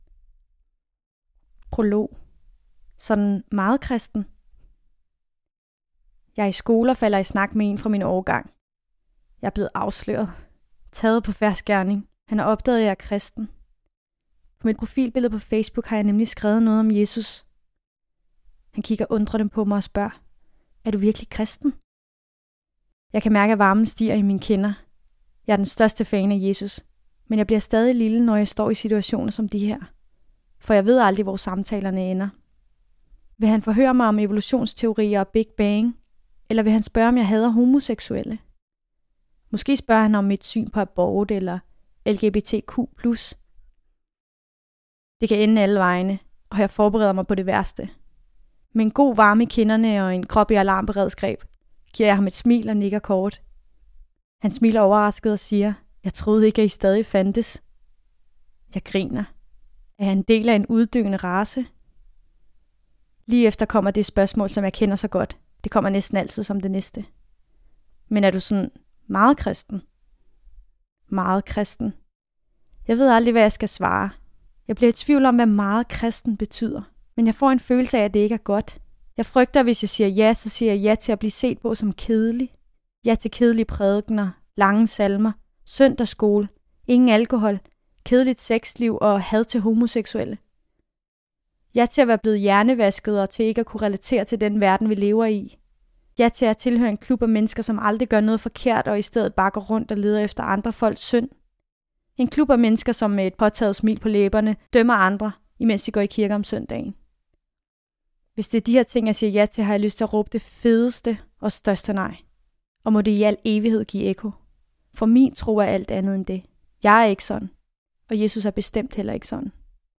Hør et uddrag af Jesus i øjenhøjde Jesus i øjenhøjde Format MP3 Forfatter Camilla Quist Bog Lydbog 149,95 kr.